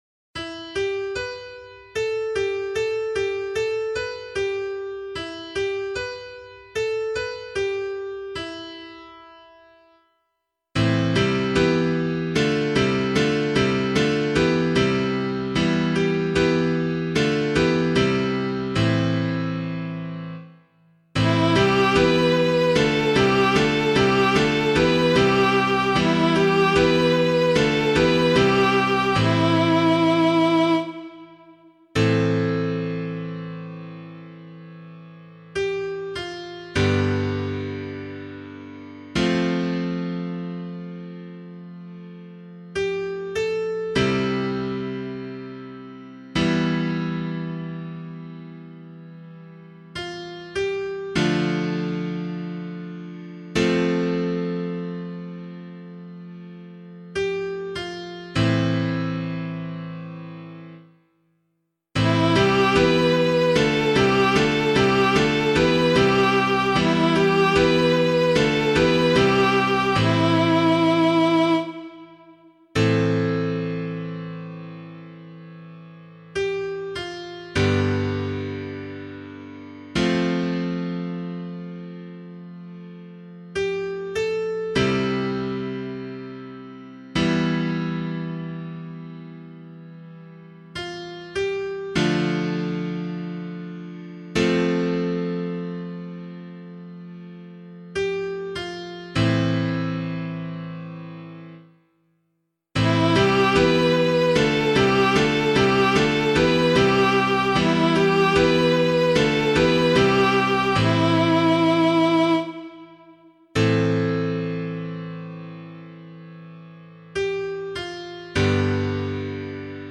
014 Lent 2 Psalm B [LiturgyShare 4 - Oz] - piano.mp3